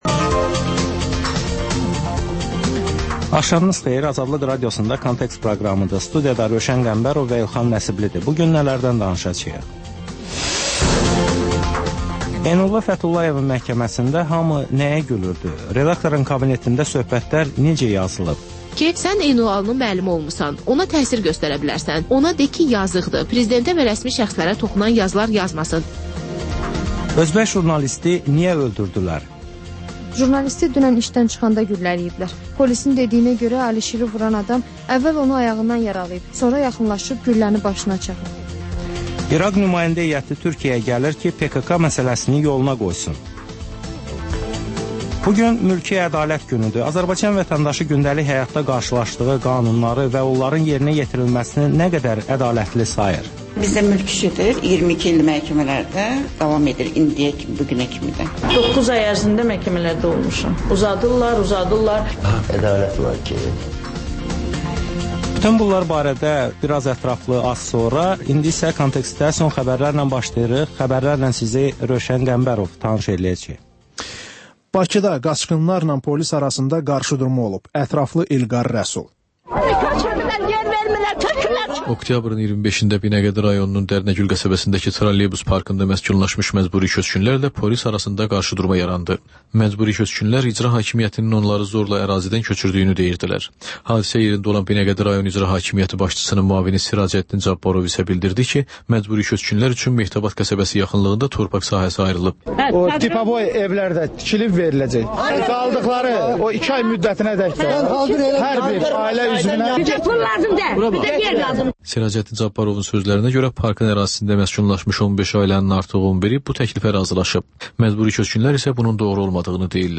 Xəbərlər, müsahibələr, hadisələrin müzakirəsi, təhlillər, sonra QAFQAZ QOVŞAĞI rubrikası: «Azadlıq» Radiosunun Azərbaycan, Ermənistan və Gürcüstan redaksiyalarının müştərək layihəsi